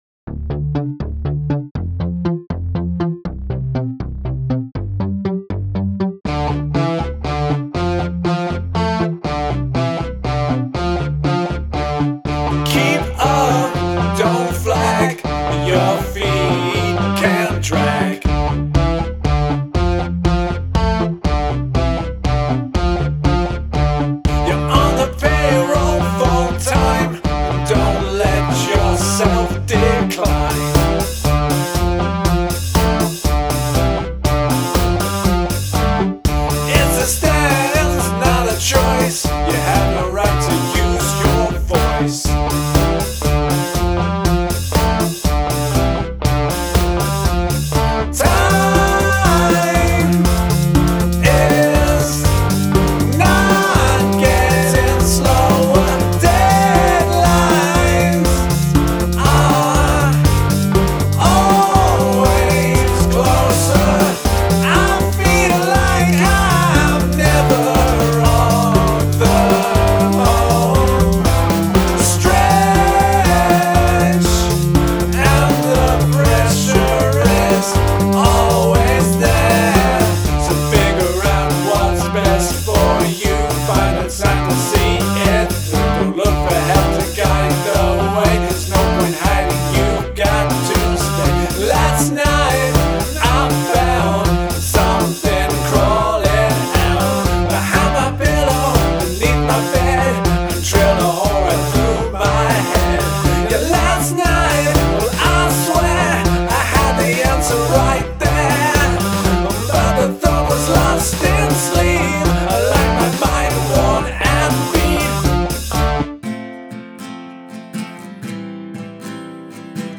Polyrhythm